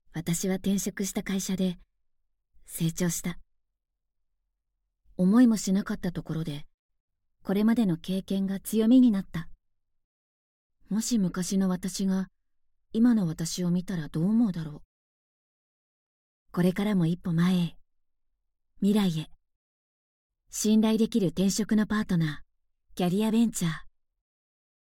中低音～低音の響く声が特徴です。透明感のあるナレーションや、倍音を活かした語りを得意としています。
モノローグ、ナチュラル